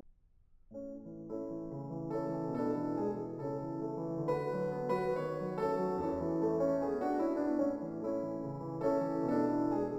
For my dataset, I chose two piano composers of different style, J.S. Bach and F. Schubert.
Then downsampled to 6.3 kHz: